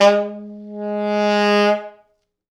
Index of /90_sSampleCDs/East West - Quantum Leap Horns Sax/Quantum Leap Horns Sax/T Sax fts